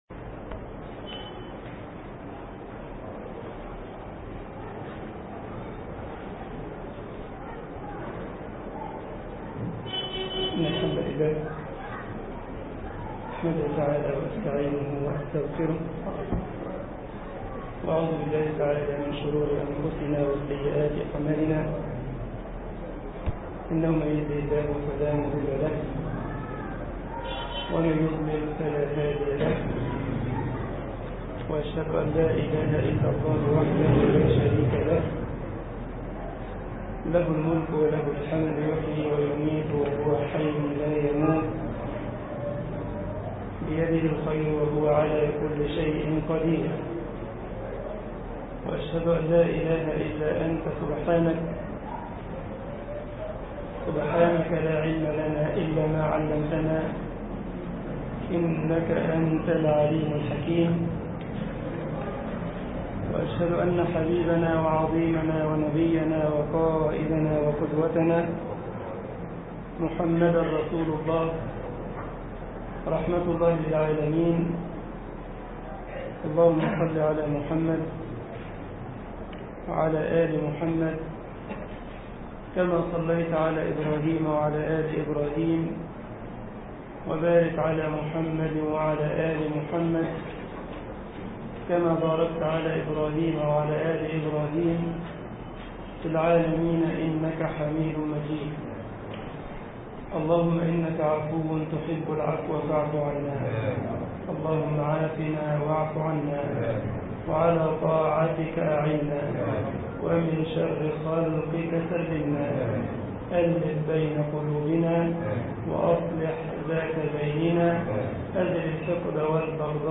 مسجد غمرة المنوفي ـ الشرابية ـ القاهرة